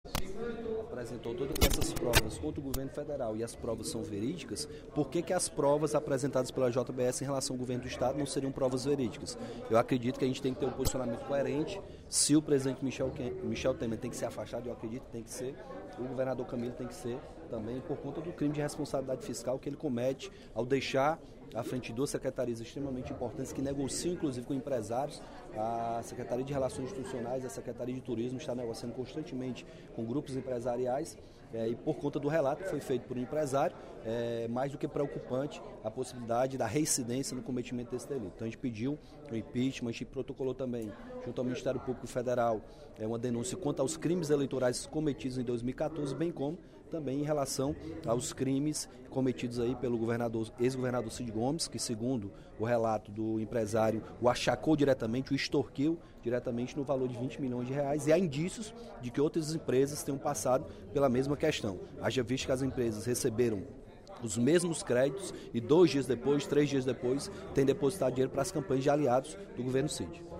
O deputado Capitão Wagner (PR) anunciou, durante o primeiro expediente da sessão plenária desta terça-feira (23/05), ter protocolado pedido de impeachment do governador Camilo Santana por improbidade administrativa.